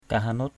/ka-ha-no:t/ (t.) công bằng.